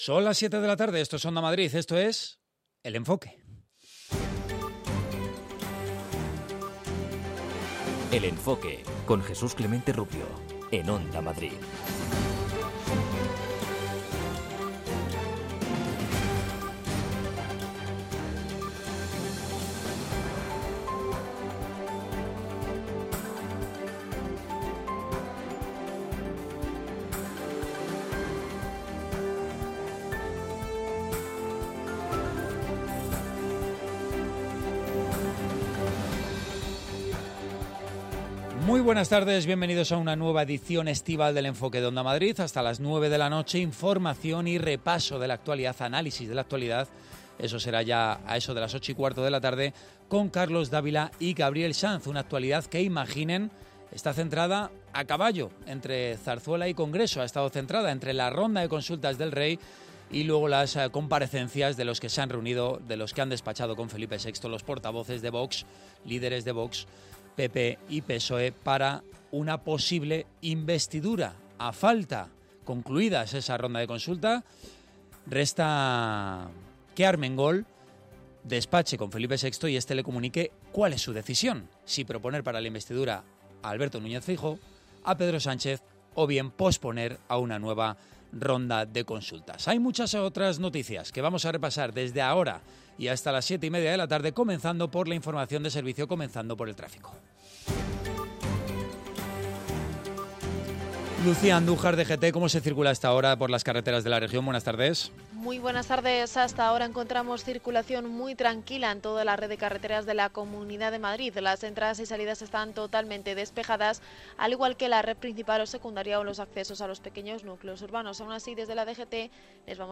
Cada tarde desde las 19 horas Félix Madero da una vuelta a la actualidad, para contarte lo que ha pasado desde todos los puntos de vista. La información reposada, el análisis, y las voces del día constituyen el eje central de este programa, con la vista puesta en lo que pasará al día siguiente.